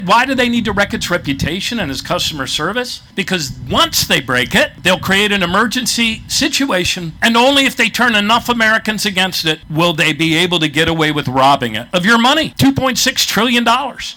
A Fells Point rally on Saturday kicked off a nationwide movement to bring attention to changes in Social Security.